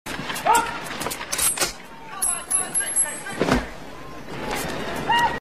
RELOADING RIFLE AND DOG BARK.mp3
Original creative-commons licensed sounds for DJ's and music producers, recorded with high quality studio microphones.
reloading_rifle_and_dog_bark_nvn.ogg